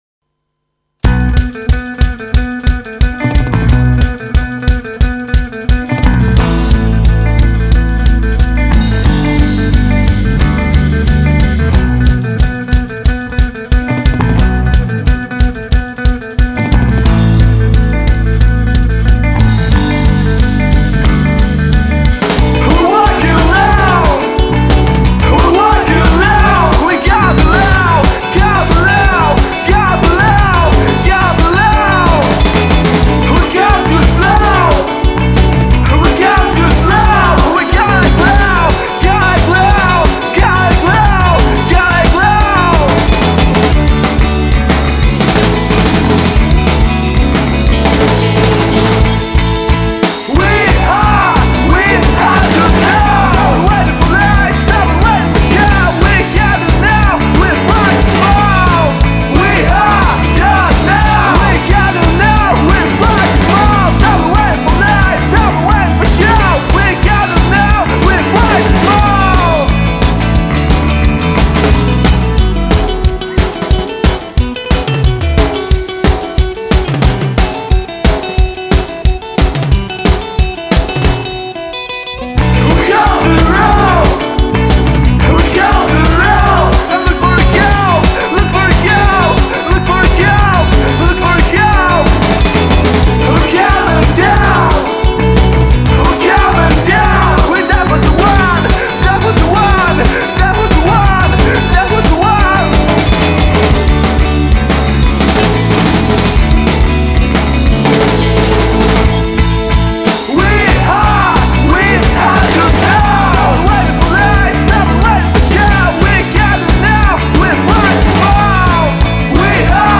Genre: Indie Rock / Dance Rock / Math Rock